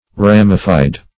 Search Result for " ramified" : The Collaborative International Dictionary of English v.0.48: Ramify \Ram"i*fy\ (r[a^]m"[i^]*f[imac]), v. t. [imp.